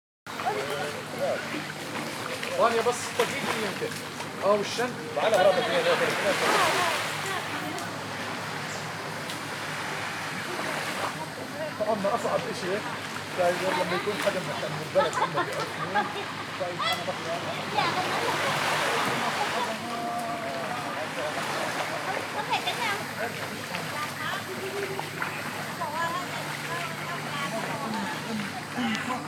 เสียงบรรยากาศ
เสียงบรรยากาศเกาะห้อง.mp3